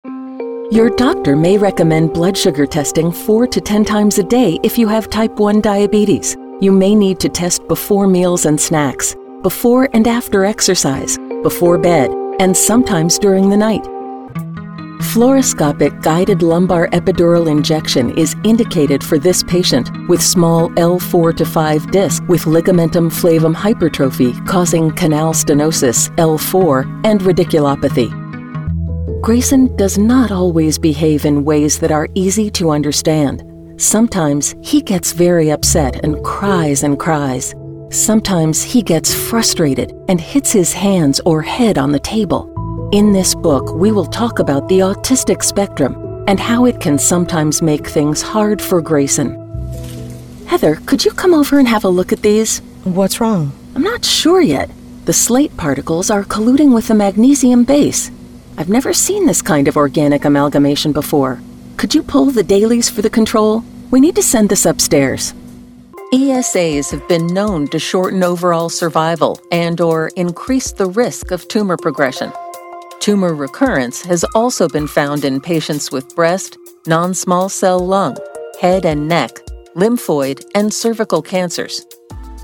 Clear, unaccented North American English voiceover.
Voice acting is conversational and natural, putting listeners at their ease while keeping their interest.
Sprechprobe: eLearning (Muttersprache):
confident, informative, knowledgeable, real sounding, conversational